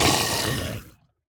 sounds / mob / husk / death2.ogg
death2.ogg